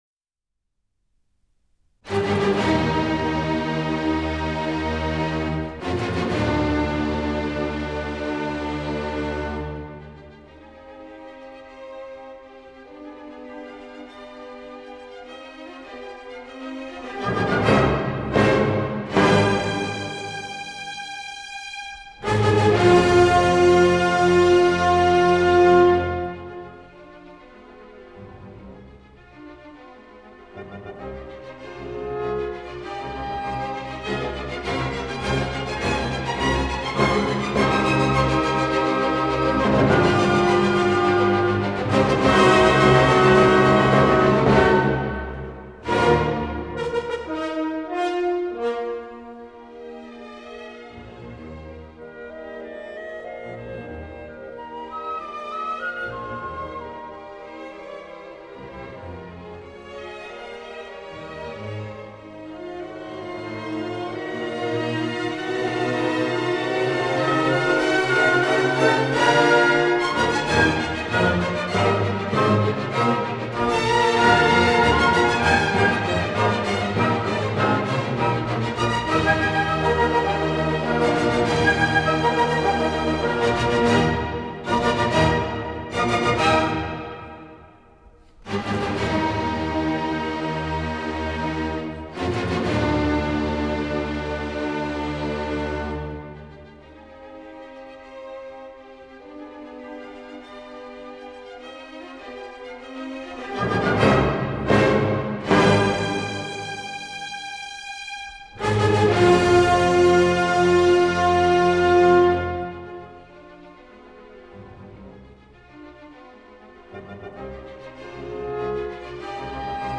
Classical